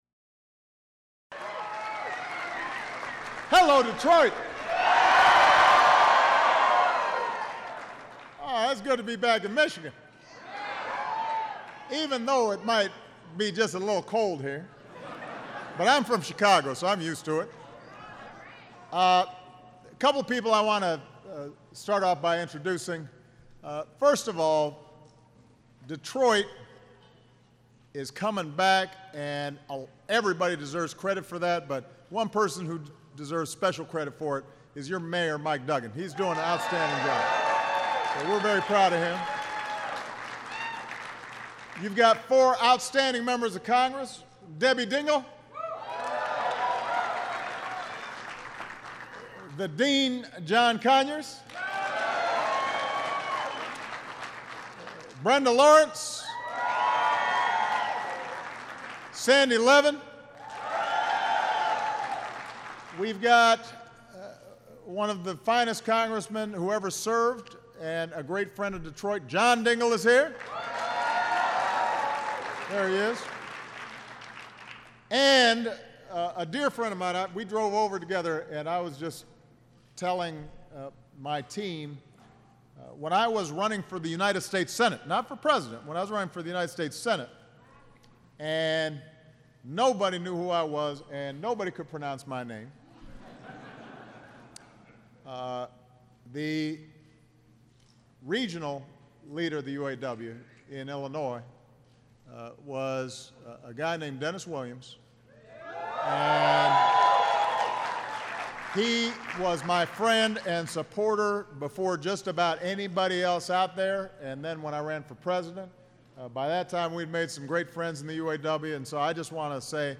Held at the UAW-GM Center for Human Resources in Detroit, Michigan.